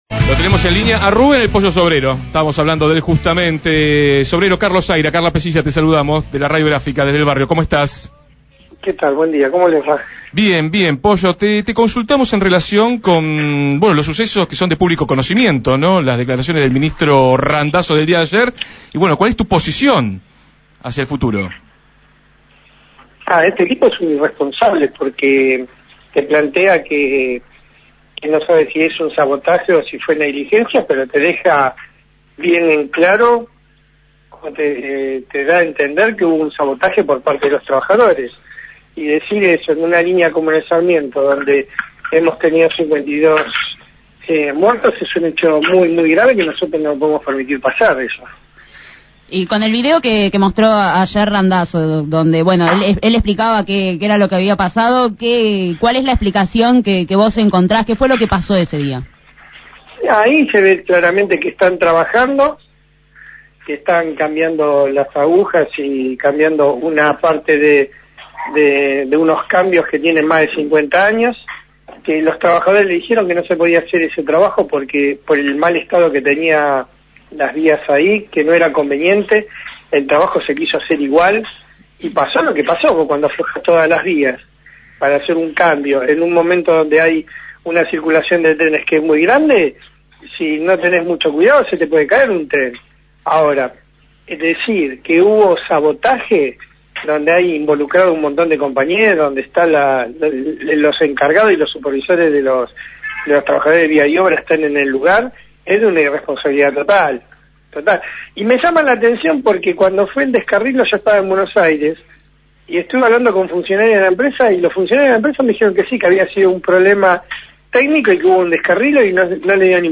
El ministro del Interior y Transporte expuso un vídeo del descarrilamiento del 30 de diciembre pasado en la Línea Sarmiento y denunció que hubo «negligencia o sabotaje». Entrevistado en Desde el Barrio